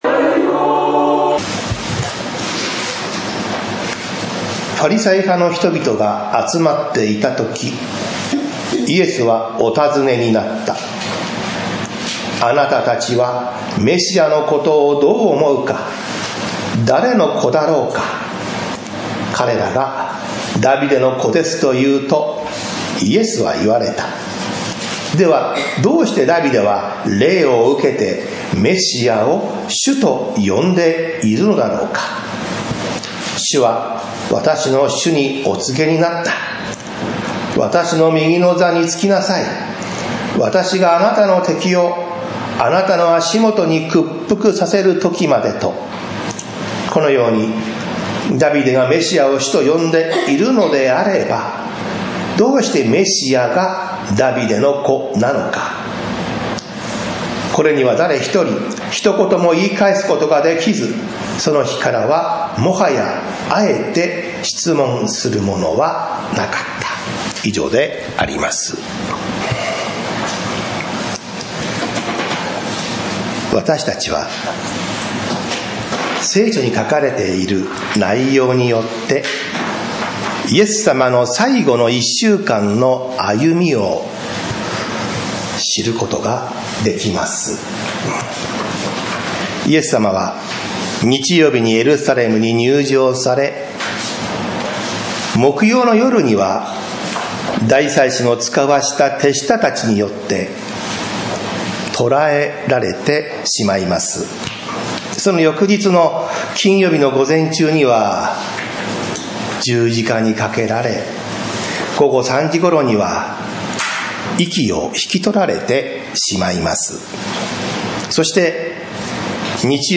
礼拝説教アーカイブ 日曜 朝の礼拝